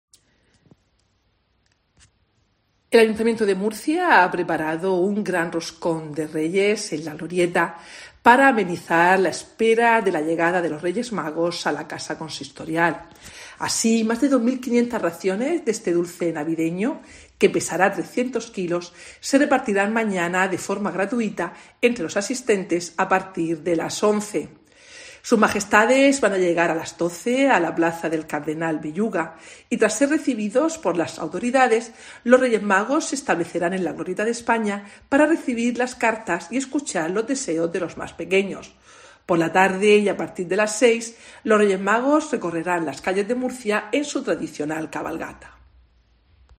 Mercedes Bernabé, concejala de Gobierno Abierto, Promoción Económica y Empleo